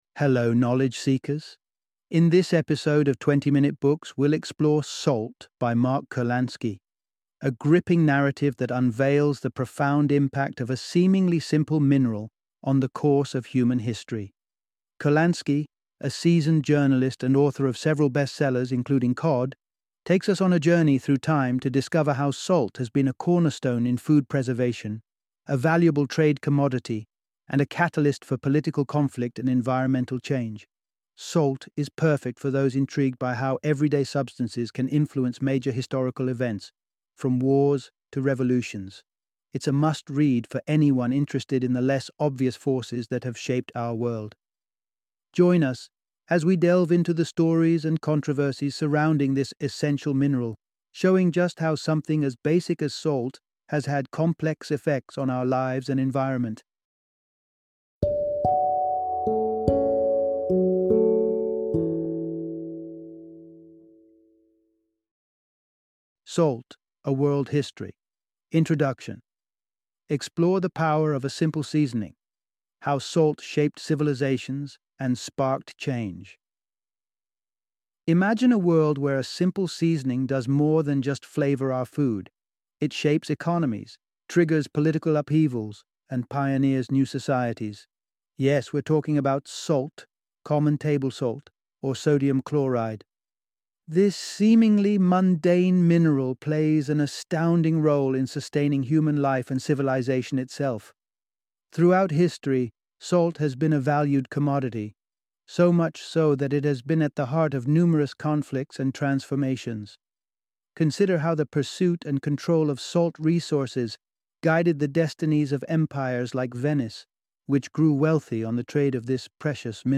Salt - Audiobook Summary